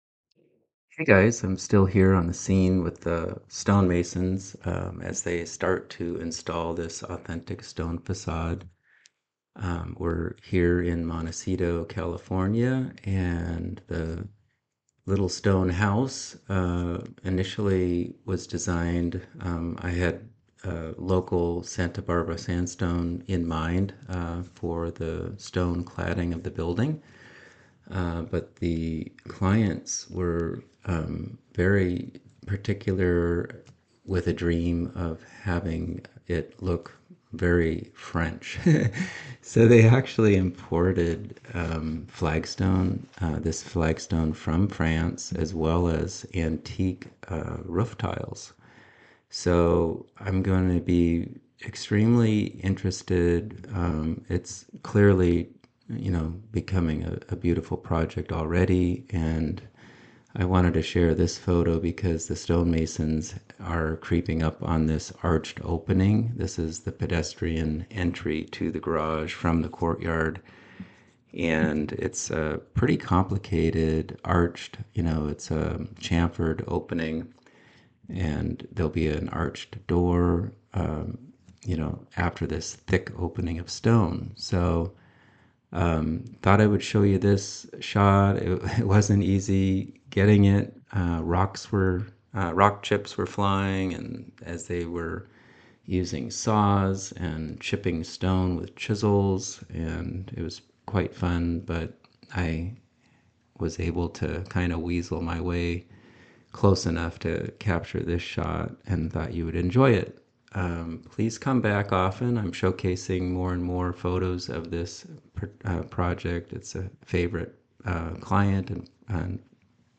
Listen as I walk you through all that was going on at this site visit while viewing the photo, below.